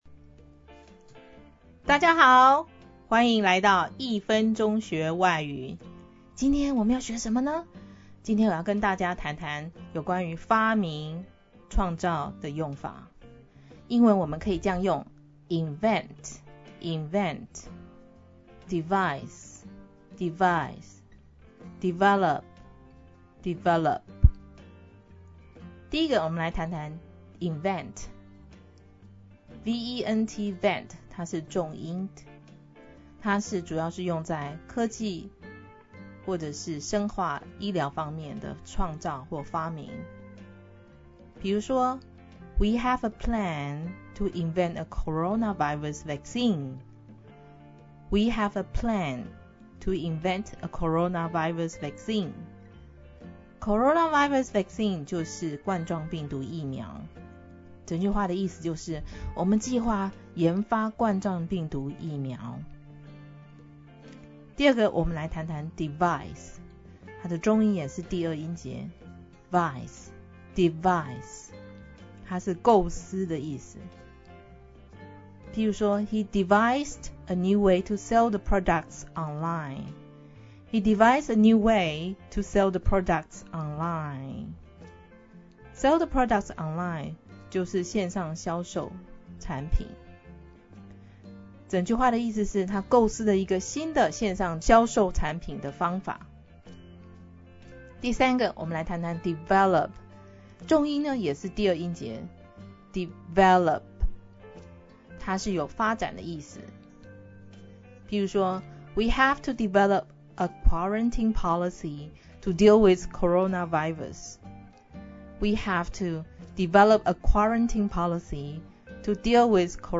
這裡幫你蒐集了生活、職場、愛情等各種情境的實用外語，聽著標準發音，跟著開口練習，每次一點點，也能累積意想不到的詞彙量喔！